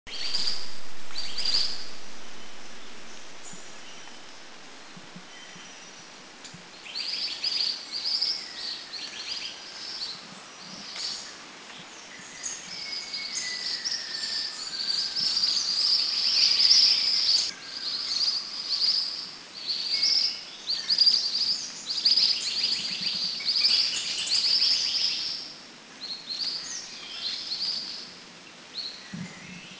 Ashy Minivet   Pericrocotus divaricatus
B2A_AshyMinivetMakiling210_SDW.mp3